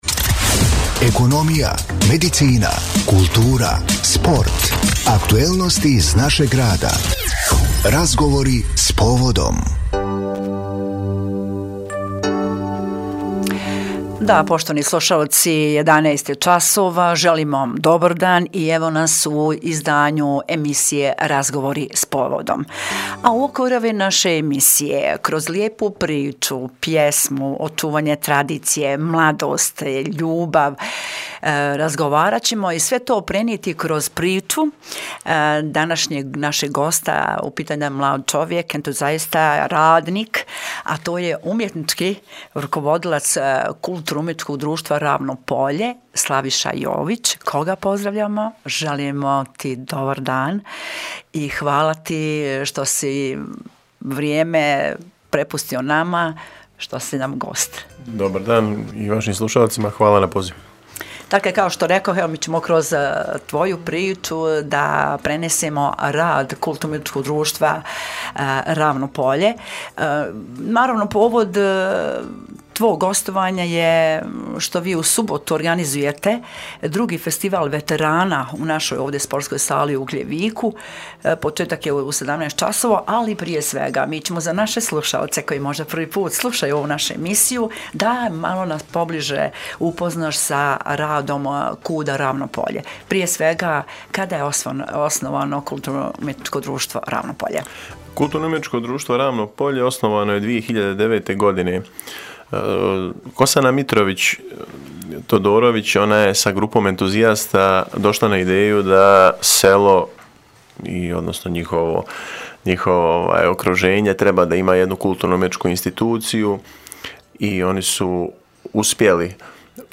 GOST U STUDIJU SKALA RADIJA